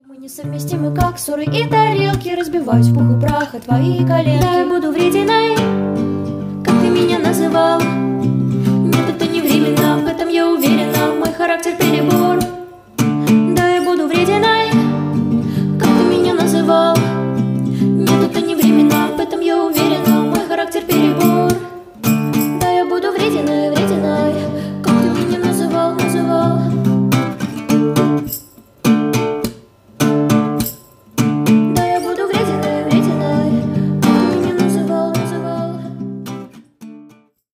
Поп Музыка
укулеле